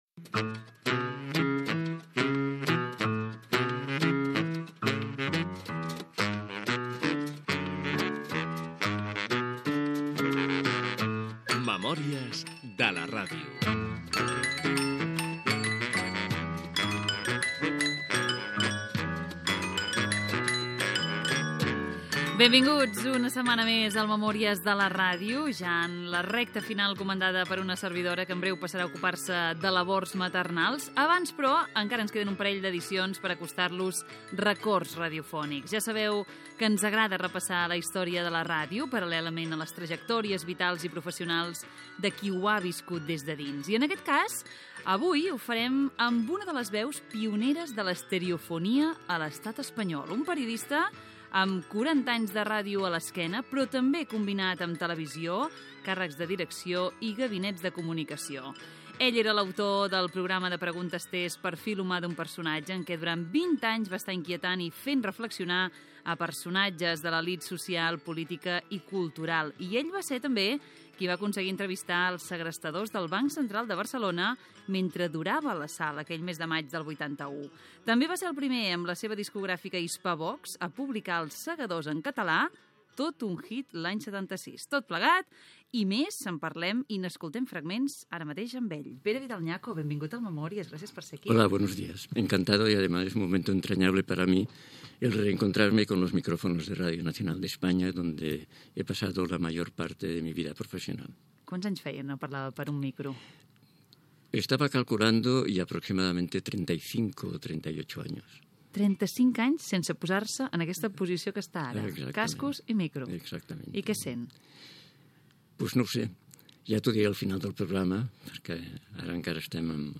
Careta del programa
Gènere radiofònic Divulgació